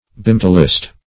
Search Result for " bimetallist" : Wordnet 3.0 NOUN (1) 1. an advocate of bimetallism ; The Collaborative International Dictionary of English v.0.48: Bimetallist \Bi*met"al*list\, n. An advocate of bimetallism.
bimetallist.mp3